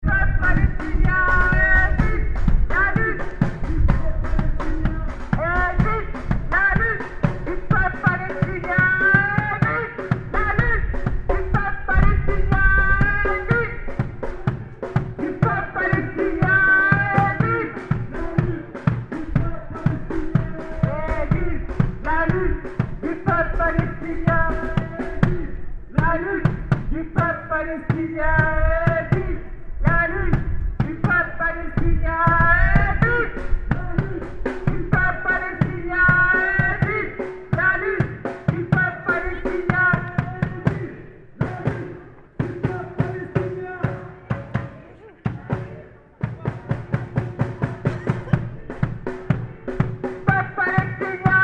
Enregistrements des prises de paroles et des slogans de la manifestation.
Slogan, rue de la liberté